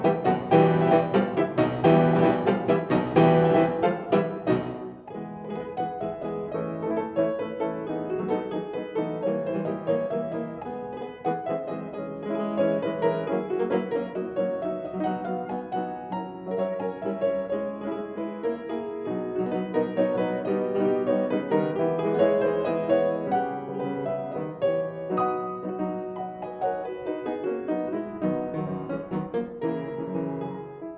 oeuvres pour piano